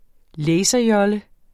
Udtale [ ˈlεjsʌ- ]